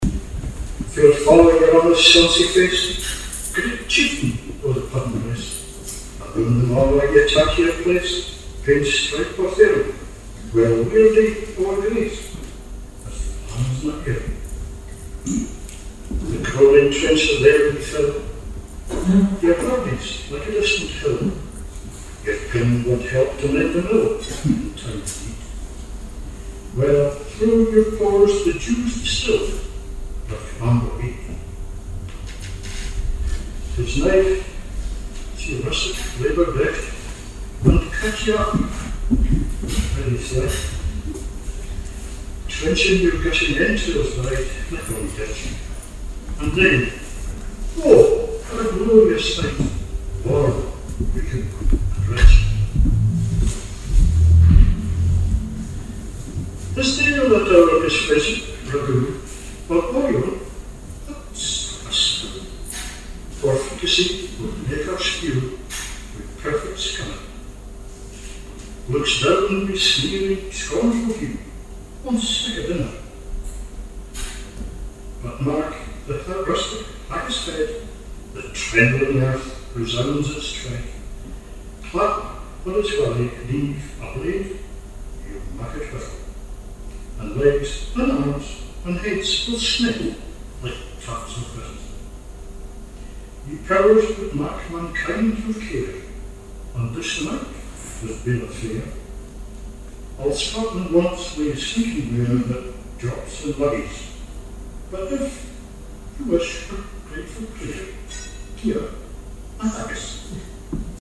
Burns Night Supper -  25 January 2019